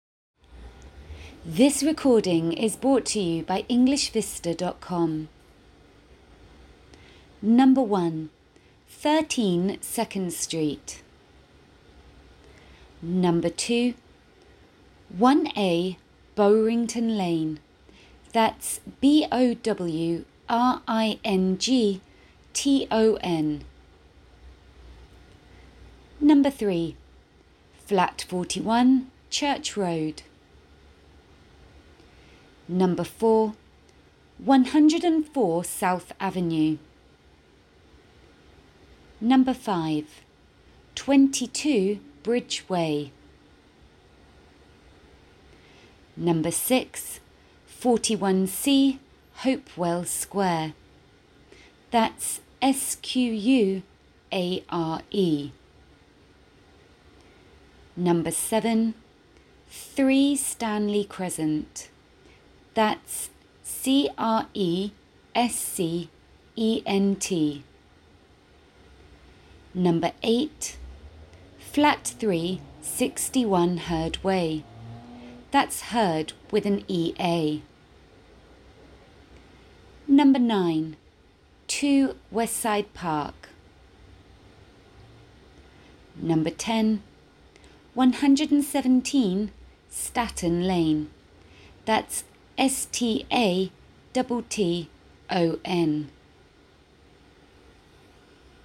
Any words that are considered difficult to spell or more unusual words will be spelt out for you on the recording.